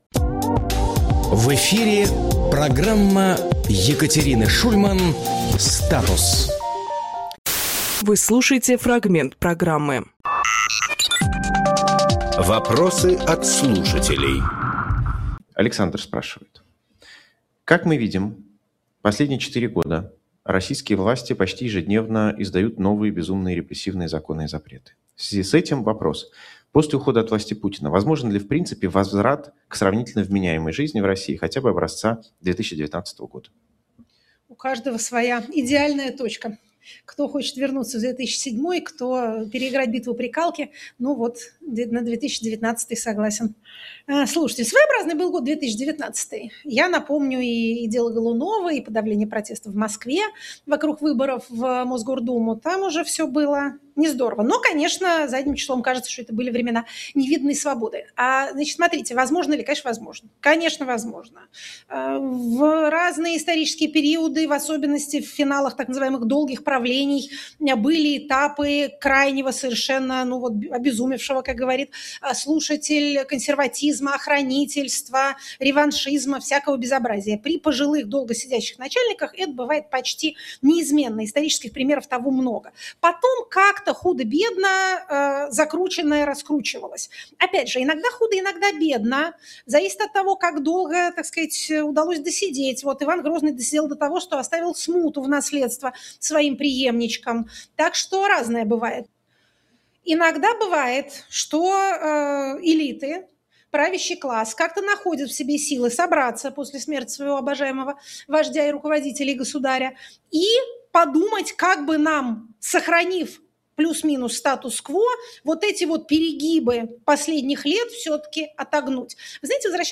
Екатерина Шульманполитолог
Фрагмент эфира от 07.04.26